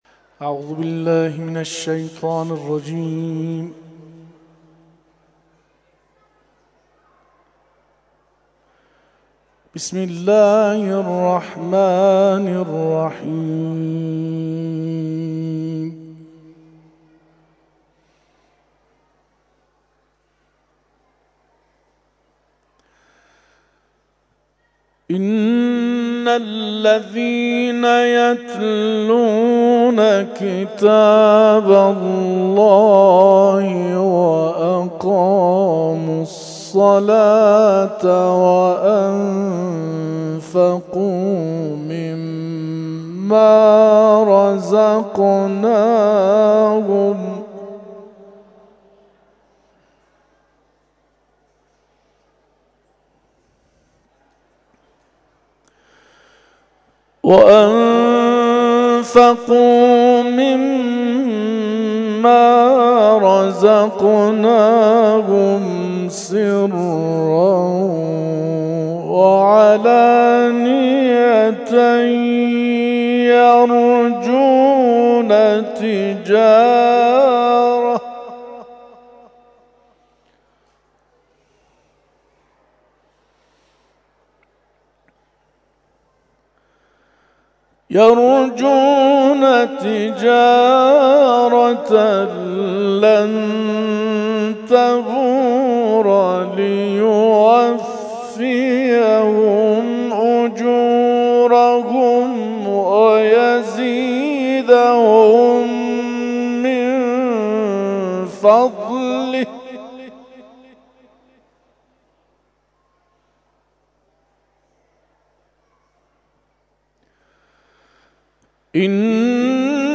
اختتامیه کلاس ‌های قرآن